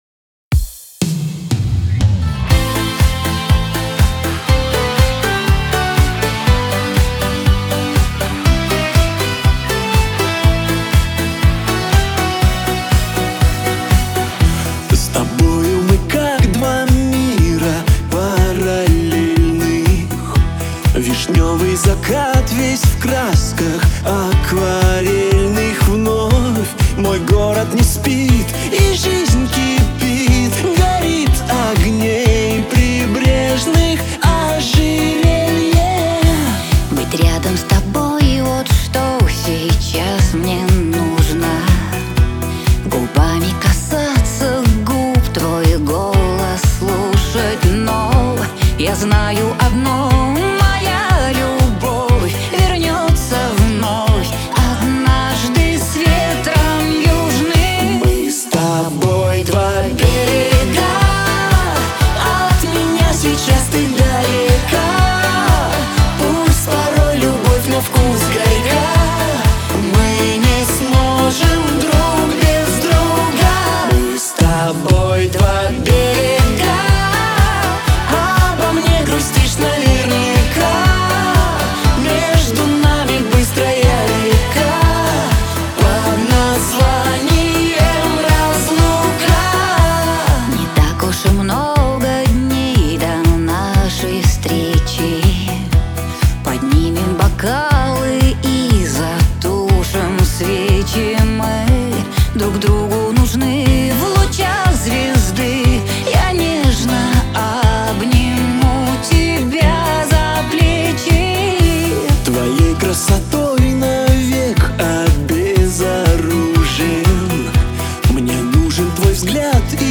эстрада
Лирика , диско
дуэт